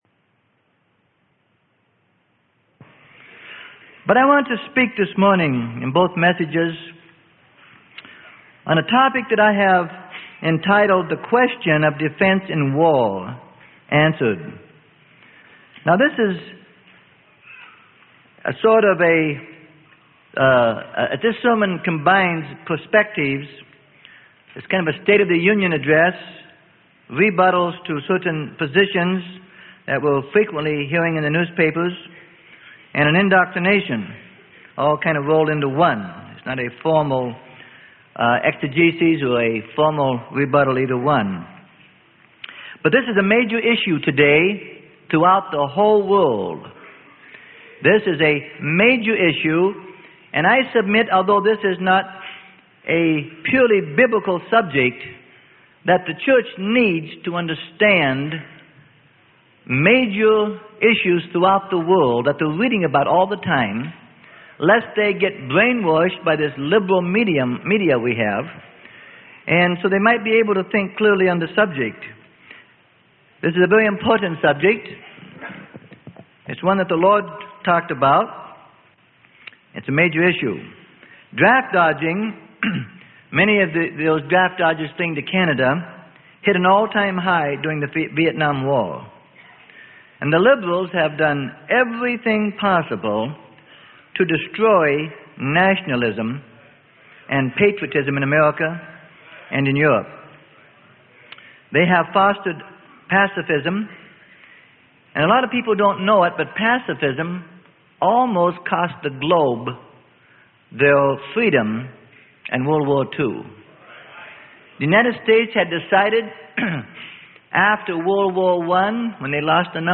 Sermon: The Question of Defense in War - Tape 2 - Freely Given Online Library